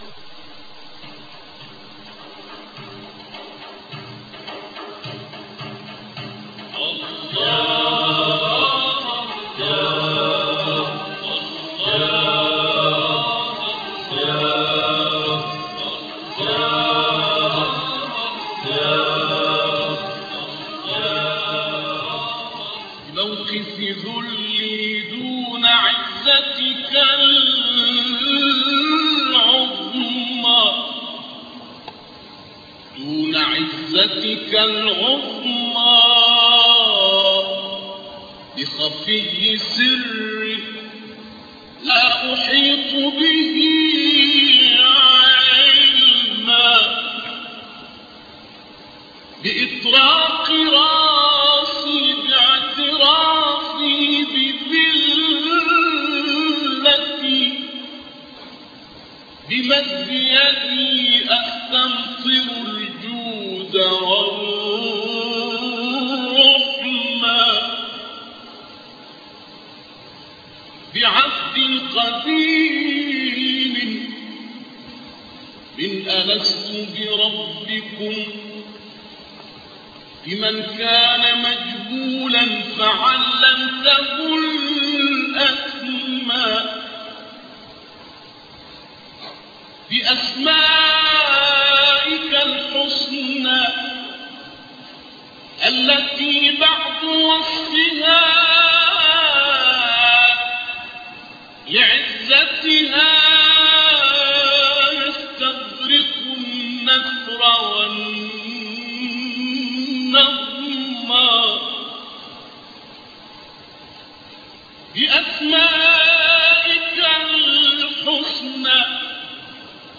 Al-Isrâ’ wa Al-Miʿrâj, Voyage nocturne et Ascension Durée : 10 min Enregistrement réalisé en Syrie.